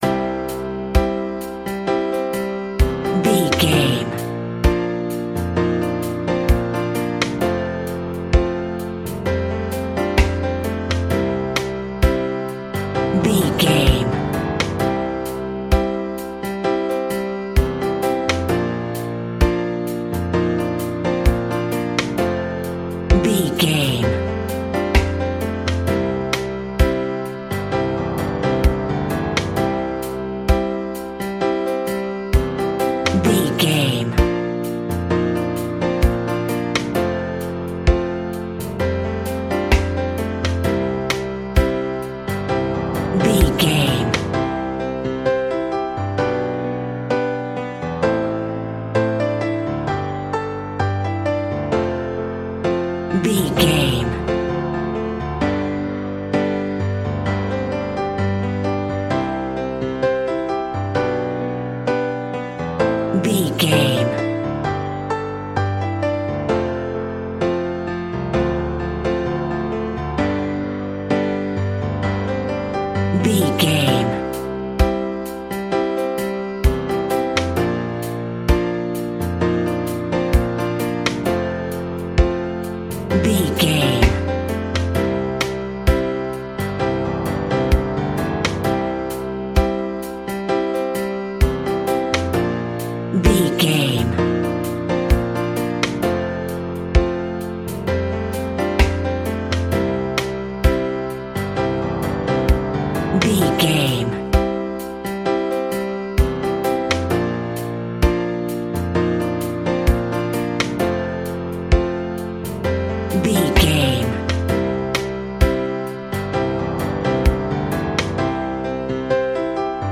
Ionian/Major
DOES THIS CLIP CONTAINS LYRICS OR HUMAN VOICE?
WHAT’S THE TEMPO OF THE CLIP?
romantic
electric guitar
bass guitar
drums
keyboard